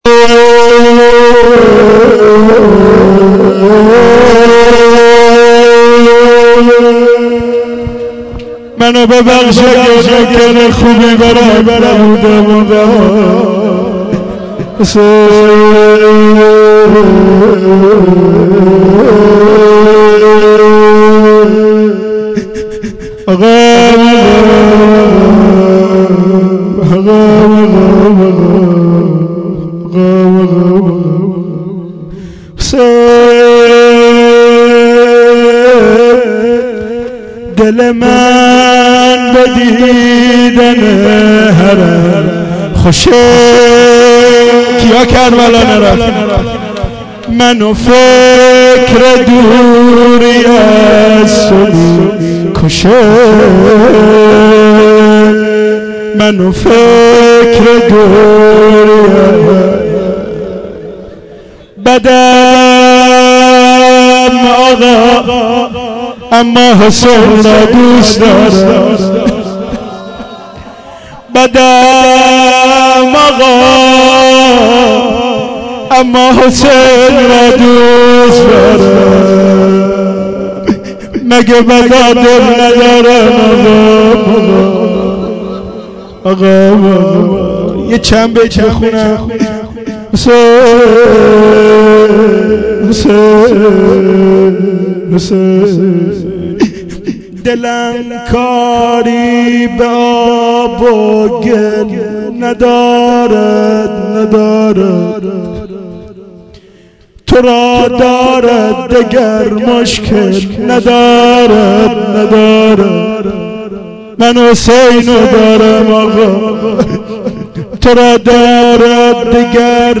shahadat-emam-reza-92-rozeh-payani-doa-2.mp3